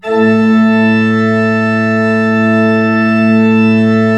Index of /90_sSampleCDs/Propeller Island - Cathedral Organ/Partition I/MAN.PLENO R